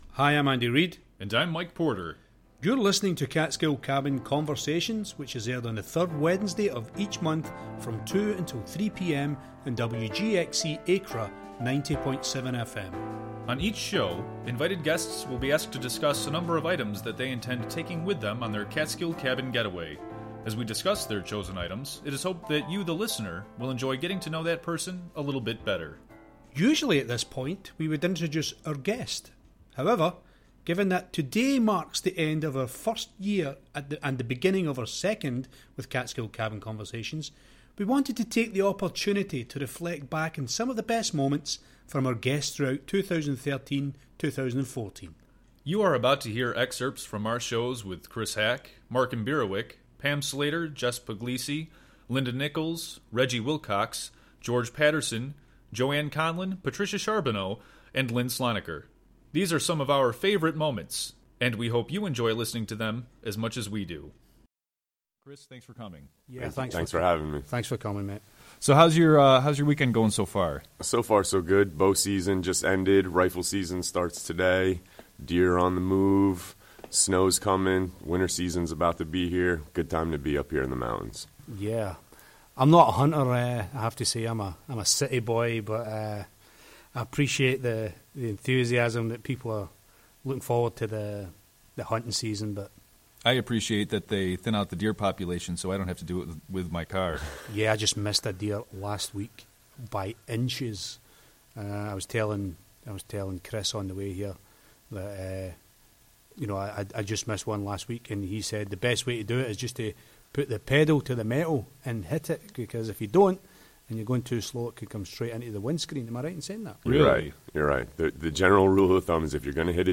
Catskill Cabin Conversations will be airing a compilation show of its guests from the past year. Tune in for an hour of fun and hilarity.